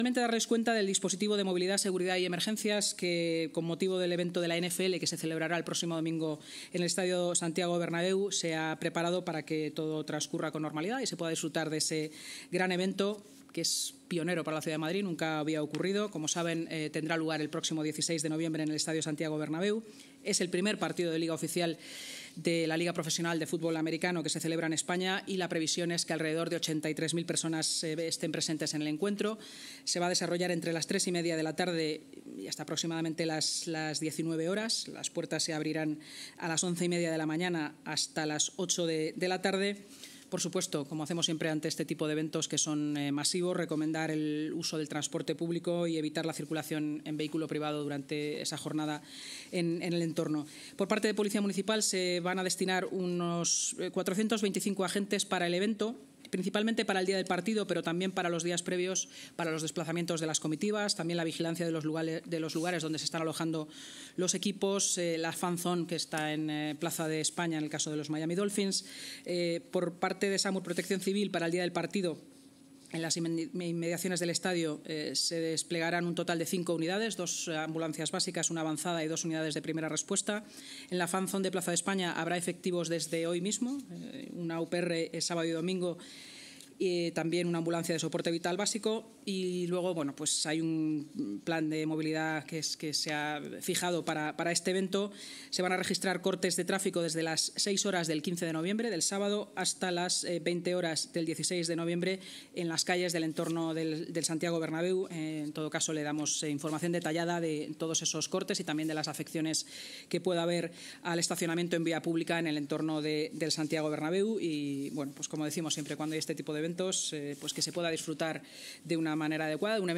Esta circunstancia conllevará que, en determinados momentos, se registren saturaciones del espacio público con repercusión en la movilidad peatonal y motorizada, ha explicado la vicealcaldesa y portavoz municipal, Inma Sanz, en la rueda de prensa posterior a la Junta de Gobierno, que ha sido informada hoy del dispositivo.